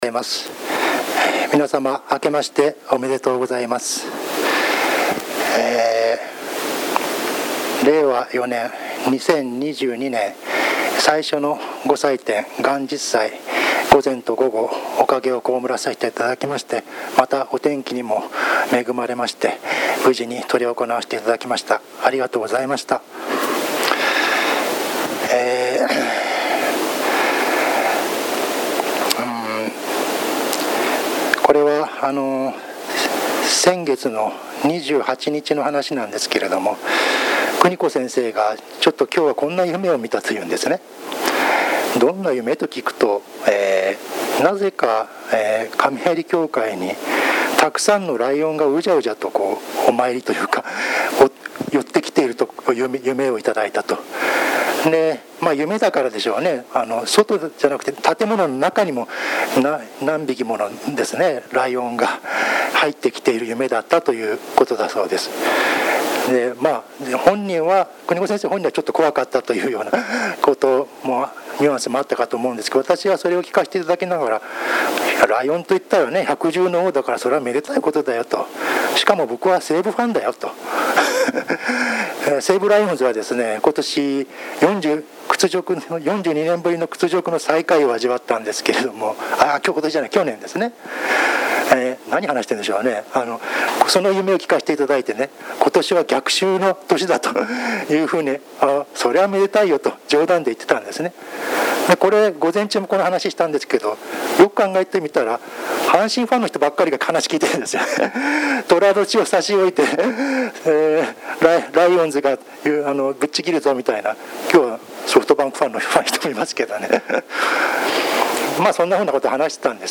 22.01.01 元日祭教話その１